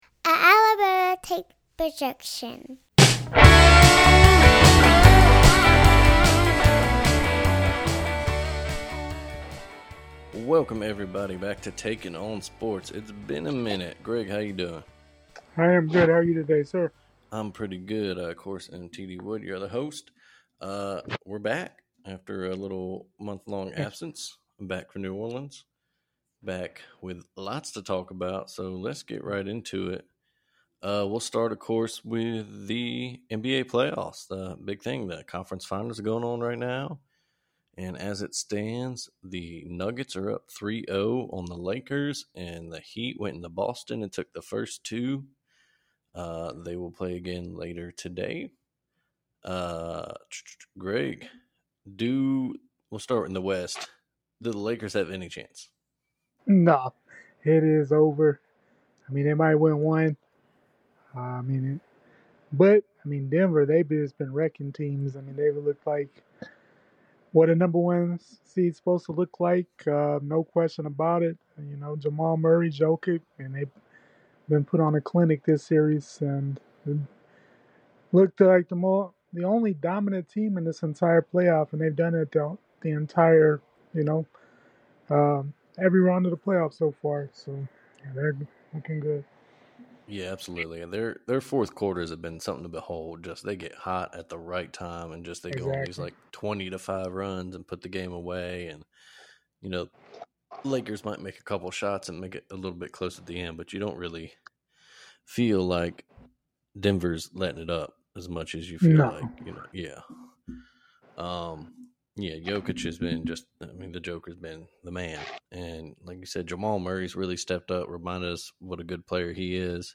Also, y'all might recognize the new intro and outro music.